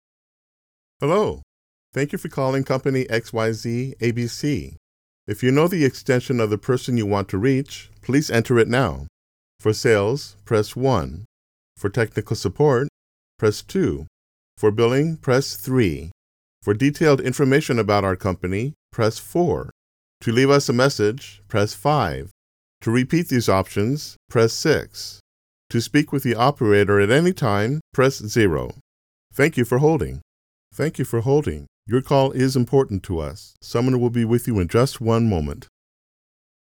IVR - INTERACTIVE VOICE RESPONSE SYSTEM
Standard American Male accent
IVR-INTERACTIVE VOICE RESPONSE.mp3